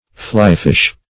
Flyfish \Fly"fish`\, n. (Zool.)